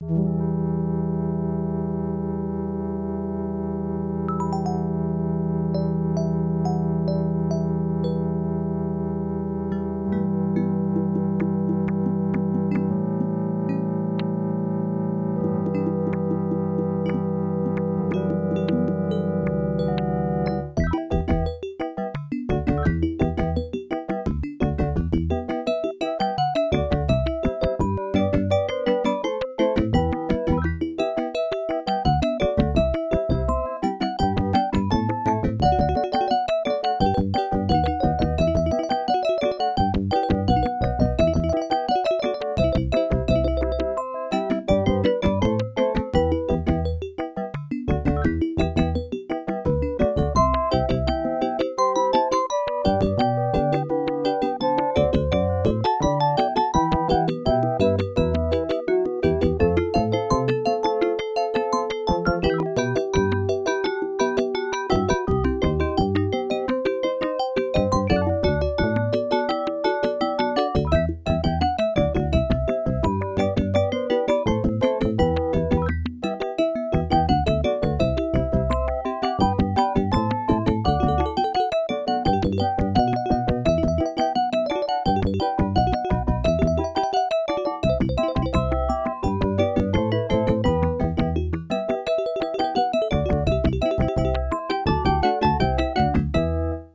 Creative SoundBlaster 1.5 ct1320(adlib driver)